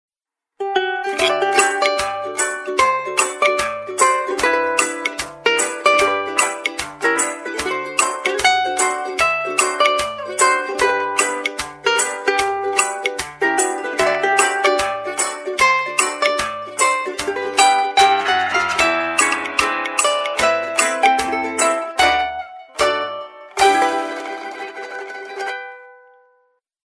Alternative Ringtones